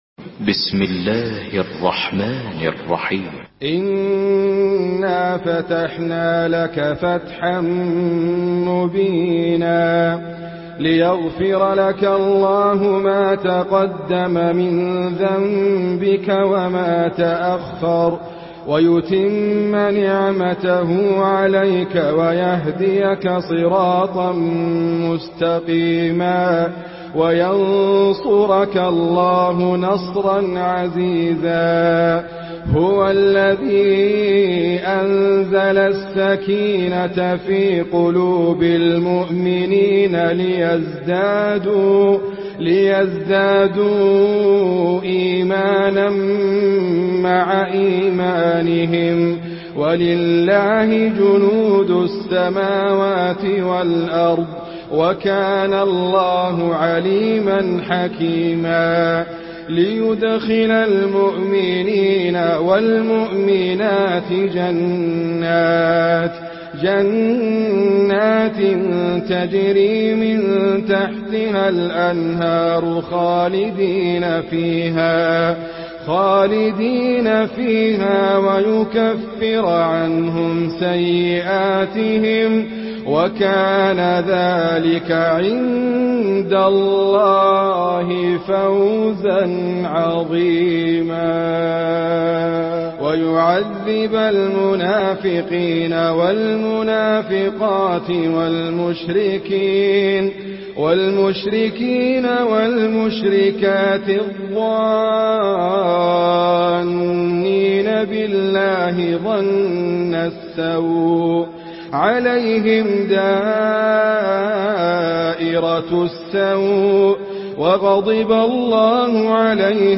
Surah Al-Fath MP3 in the Voice of Idriss Abkar in Hafs Narration
Murattal Hafs An Asim